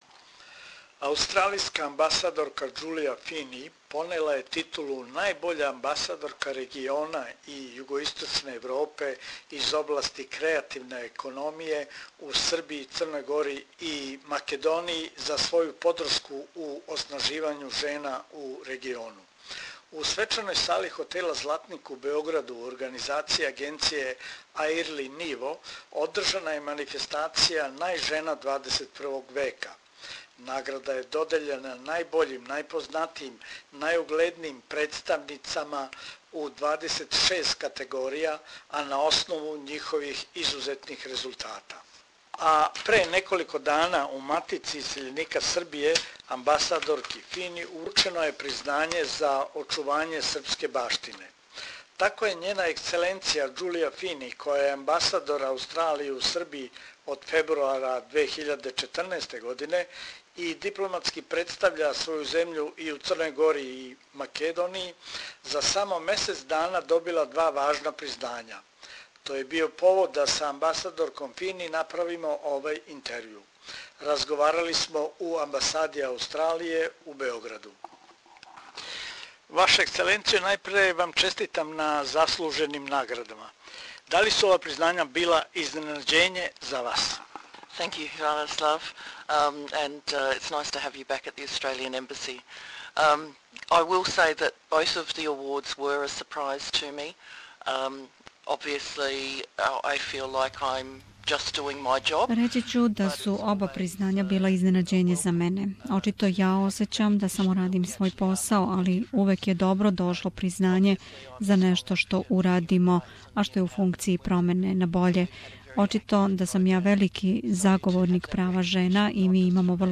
Аустралисјка амбасадорка у Београду Ђулија Фини понела је пре месец дана титулу најбоље амбасадорке региона Балкана и Југоисточне Европе из области креативне економије у Србији, Црној гори и Бившој Jугословенској Rепублици Македонији за подршку у оснаживању жена у региону, А пред сам крај године у Матици исељеника Србије амбасадорки Фини је уручено признање за очување српске баштине. То је био повод да је замолимо за разговор
посетио је у њеној резиденцији у Београду.